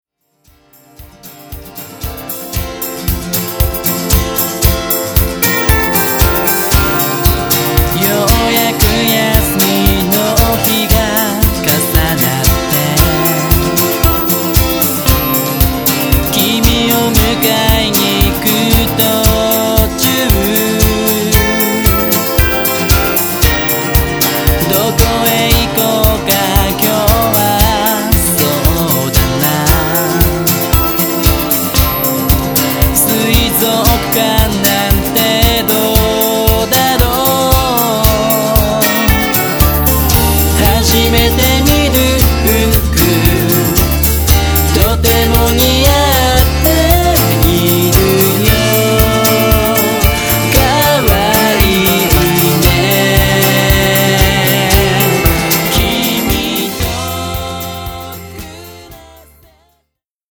特にフックでのメロとコードの付け方にハイセンスな才能を感じる。
1番でコメントしたデモよりもアレンジが凝ったつくりになっています。